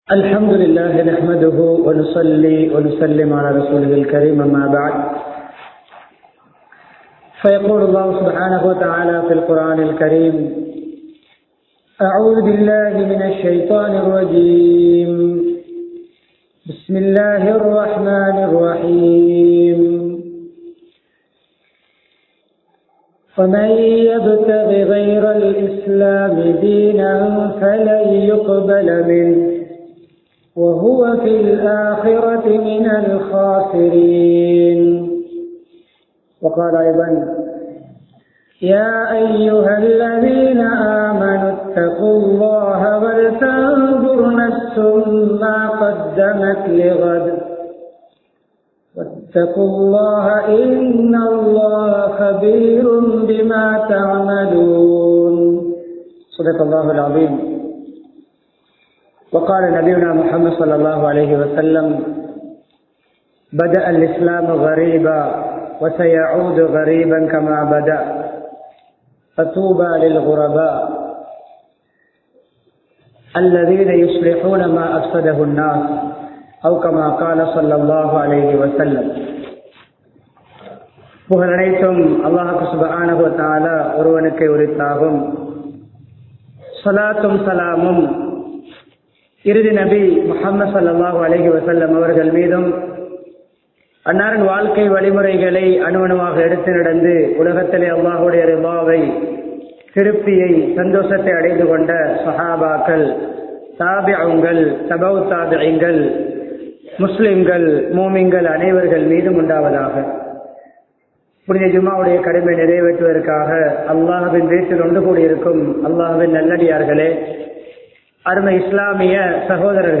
ஆண்மீகத்தின் யதார்த்தம் | Audio Bayans | All Ceylon Muslim Youth Community | Addalaichenai
Kurunegala, Hettipola Jumua Masjith